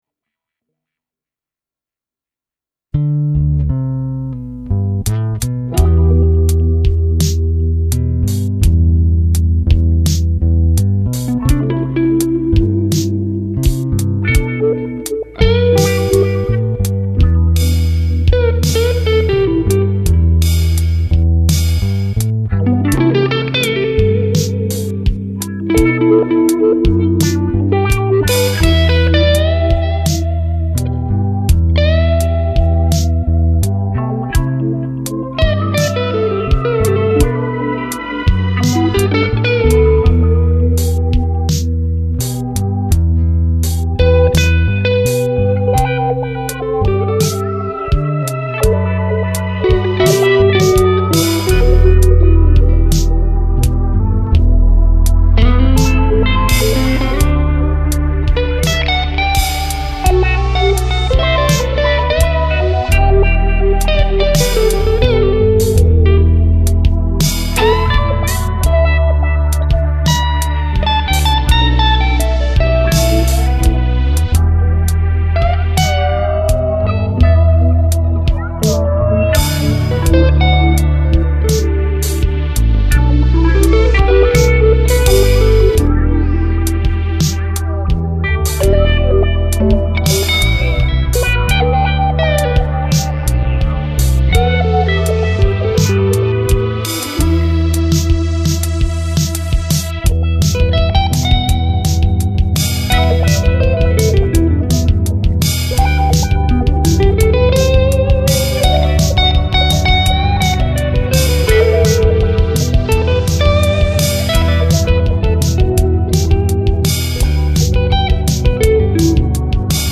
gestern Nacht brauchte ich mal etwas Ablenkung und da ich zufälligerweise mal meine Axe-Fx zuhause hatte, habe ich endlich meinen Take zum 90. aussenjam eingespielt.